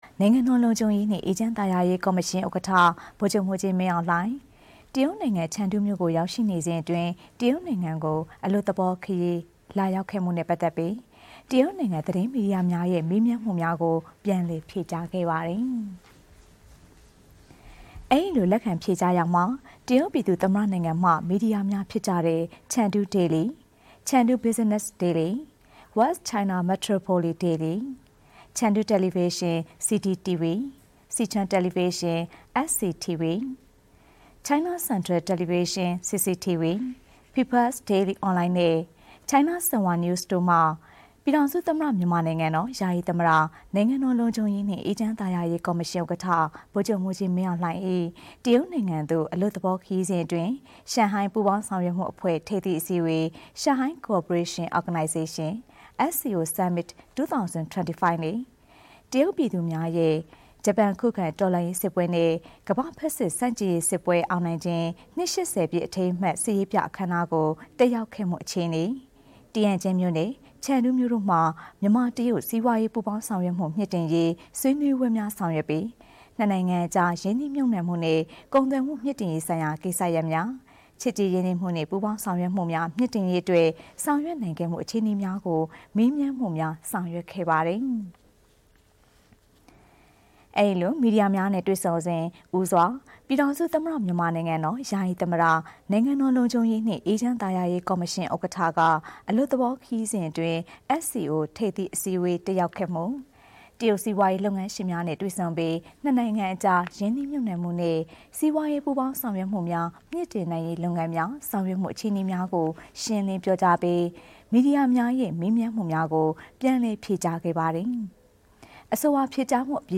ပြည်ထောင်စုသမ္မတမြန်မာနိုင်ငံတော် ယာယီသမ္မတ နိုင်ငံတော်လုံခြုံရေးနှင့် အေးချမ်းသာယာရေးကော်မရှင်ဥက္ကဋ္ဌ ဗိုလ်ချုပ်မှူးကြီး မင်းအောင်လှိုင် ချန်ဒူးမြို့၌ မီဒီယာများ၏ မေးမြန်းမှုများအား ပြန်လည်ဖြေကြား